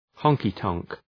honky-tonk.mp3